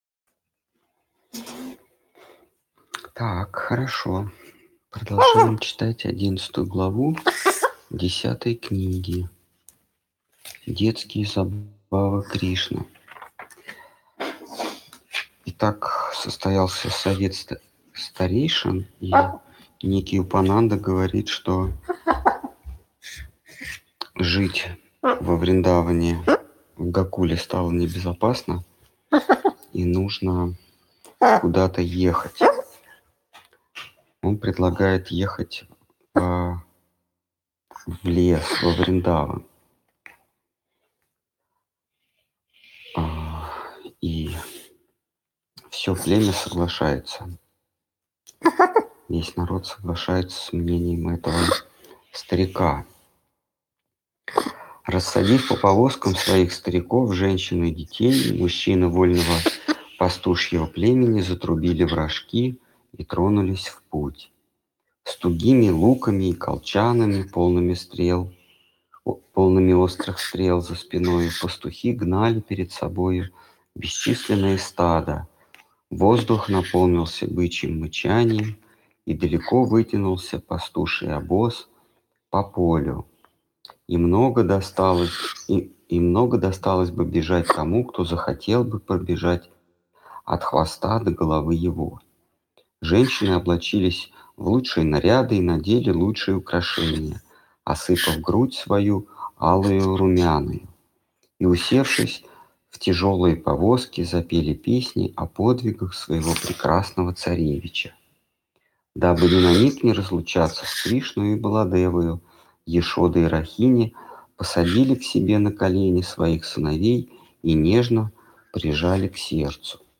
Ответы на вопросы из трансляции в телеграм канале «Колесница Джаганнатха». Тема трансляции: Шримад Бхагаватам.